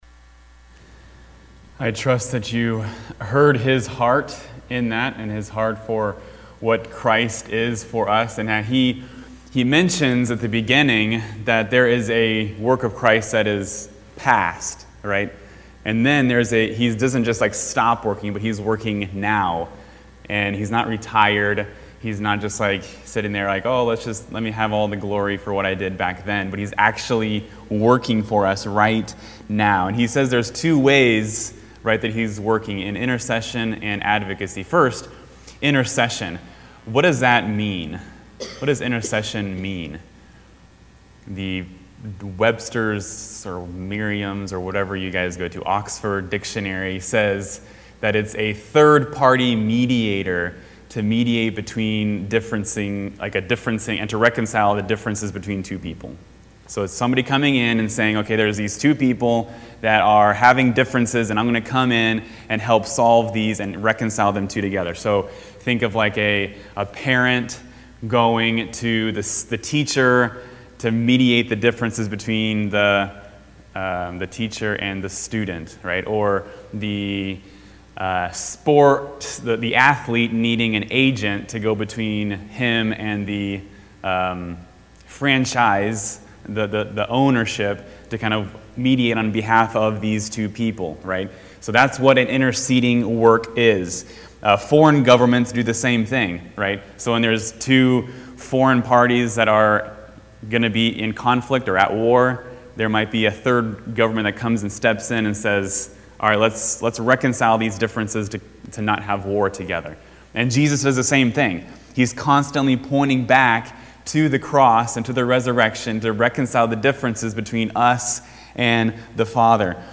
In this Adult Sunday School class